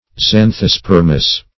Search Result for " xanthospermous" : The Collaborative International Dictionary of English v.0.48: Xanthospermous \Xan`tho*sper"mous\, a. [Xantho- + Gr. spe`rma sperm.]
xanthospermous.mp3